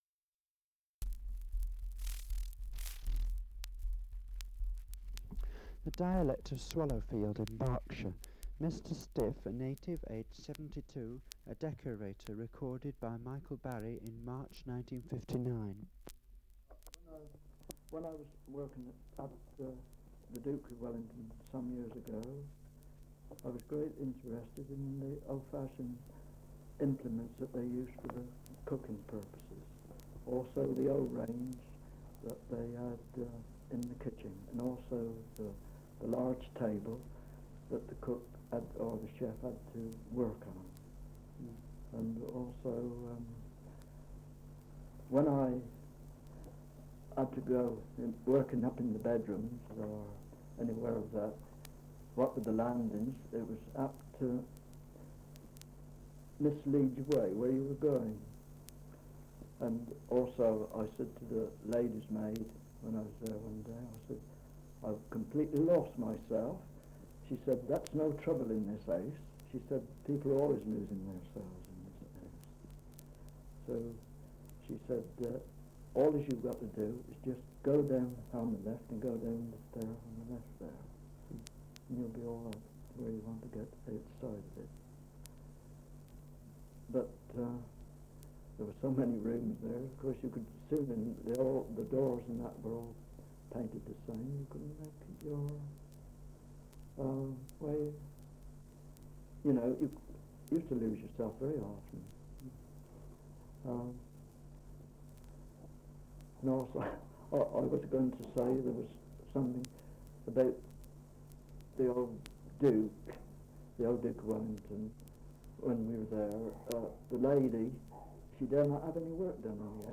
Survey of English Dialects recording in Swallowfield, Berkshire.
78 r.p.m., cellulose nitrate on aluminium